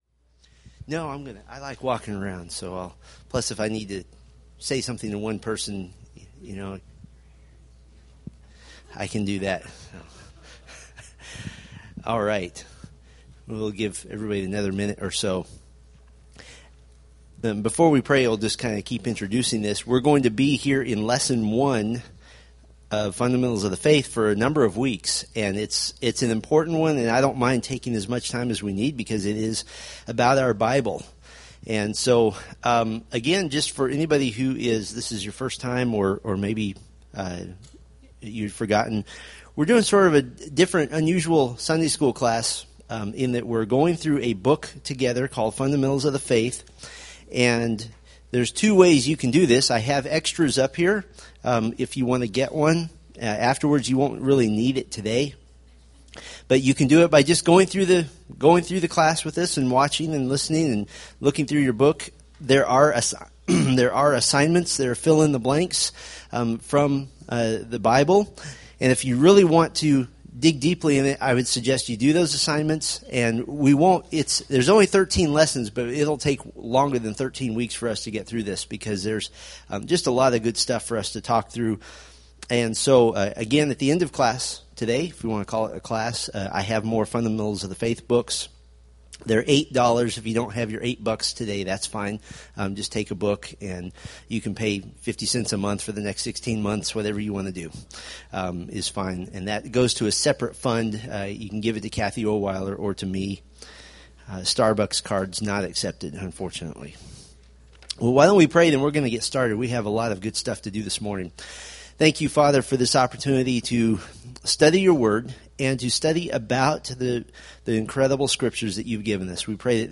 Date: Apr 21, 2013 Series: Fundamentals of the Faith Grouping: Sunday School (Adult) More: Download MP3